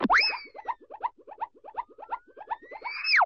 CARTOON - SPIN 05
Category: Sound FX   Right: Both Personal and Commercial